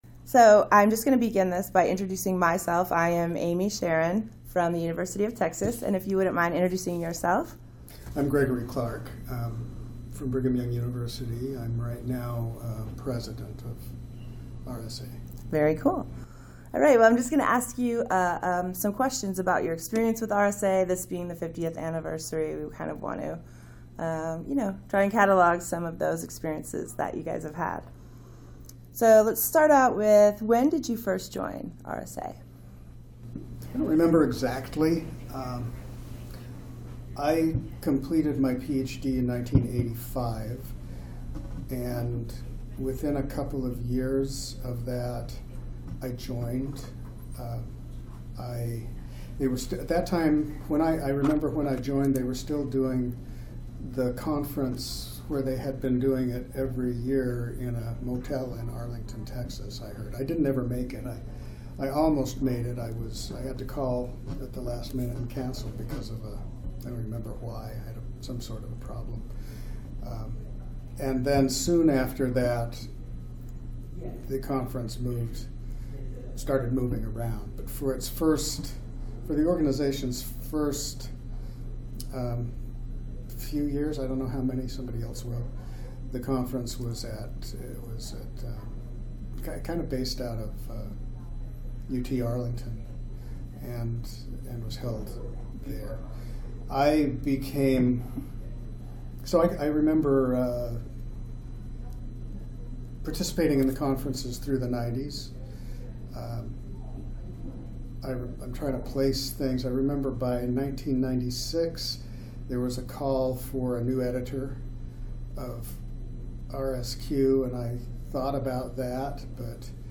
Oral History
Location 2018 RSA Conference in Minneapolis, Minnesota